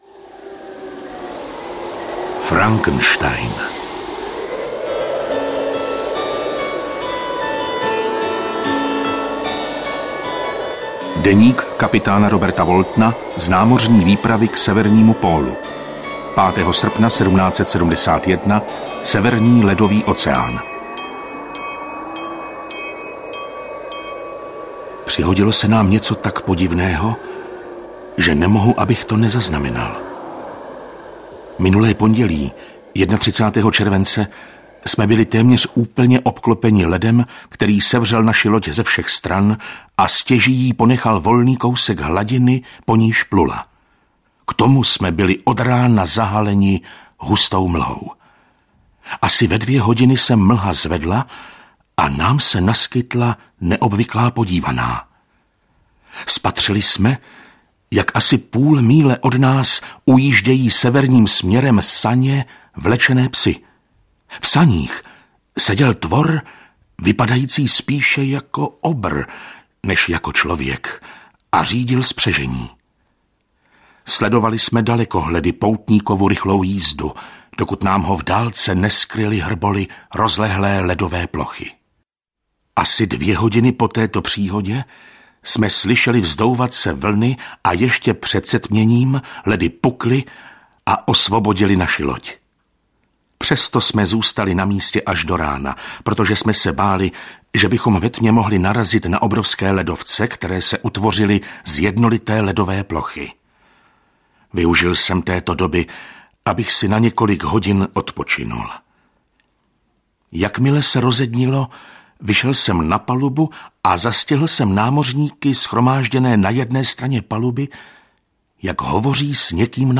Frankenstein audiokniha
Ukázka z knihy